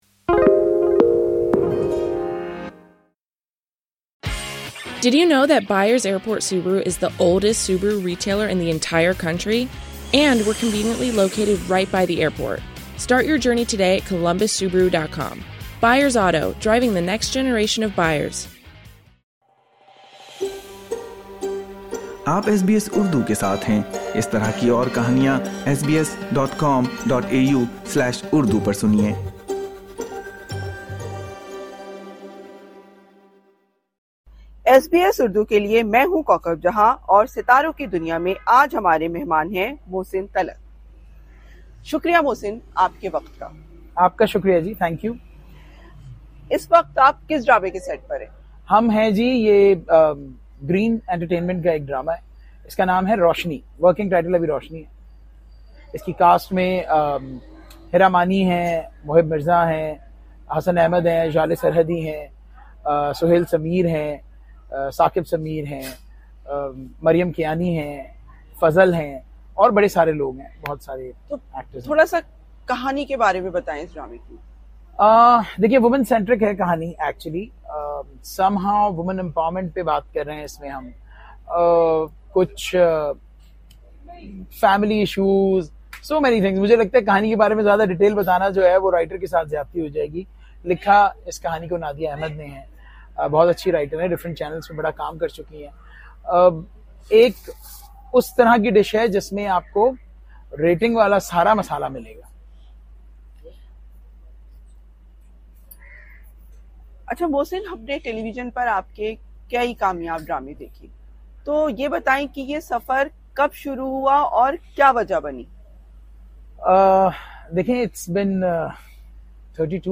خصوصی گفتگو